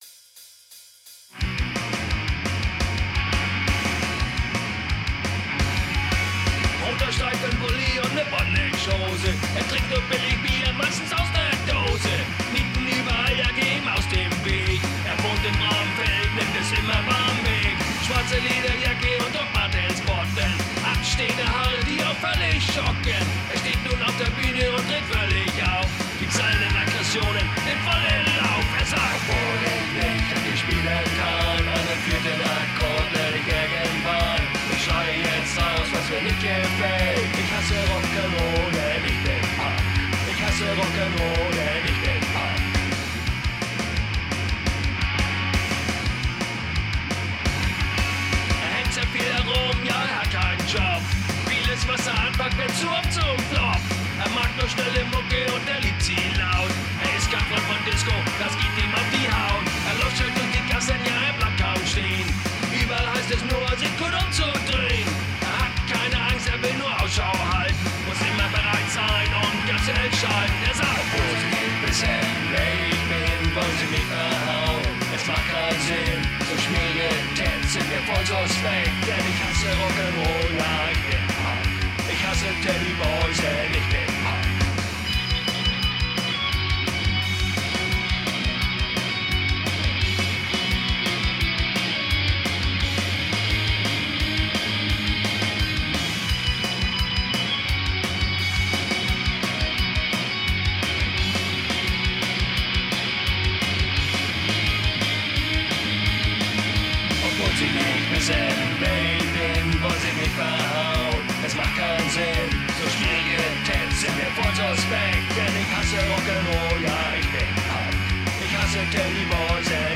low quality web version
Lead Vocals
Doom Bass
Death Drums